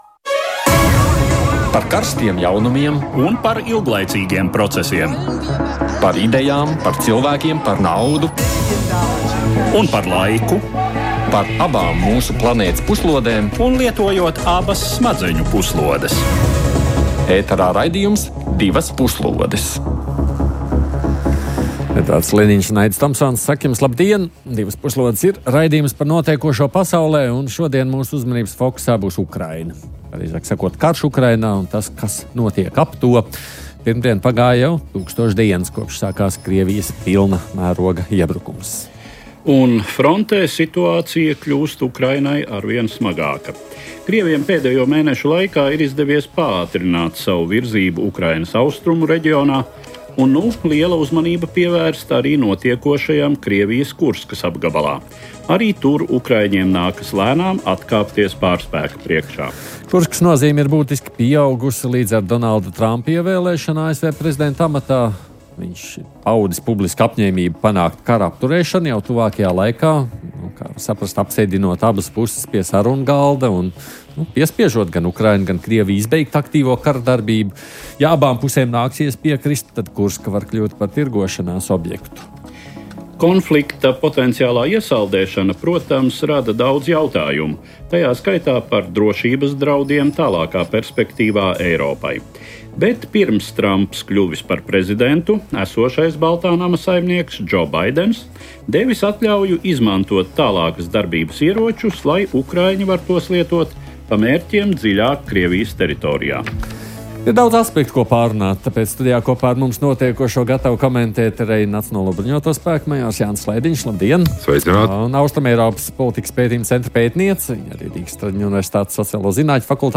Raidījums par ārpolitikas aktualitātēm, kurā kopā ar ekspertiem un ārpolitikas pārzinātājiem apspriežam un analizējam nedēļas svarīgākos notikumus pasaulē.